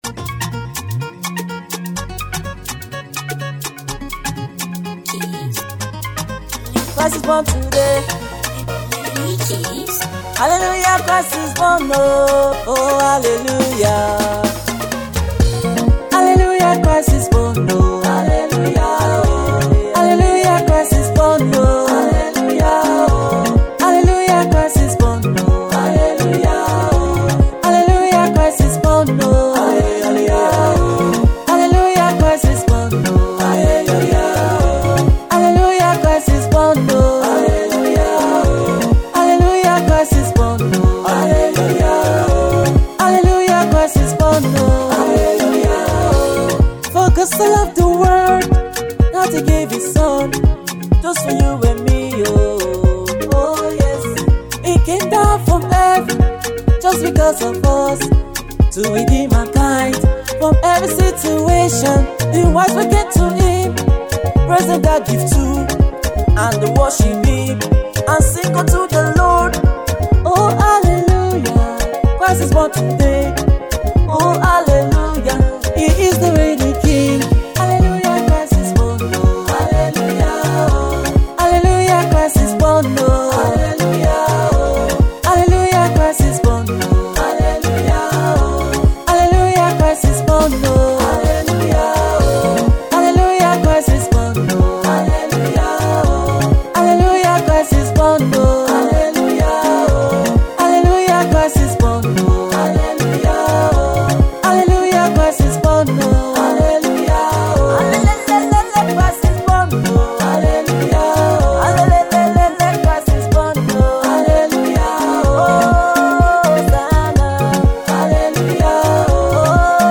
A very danceable song, songs of celebration.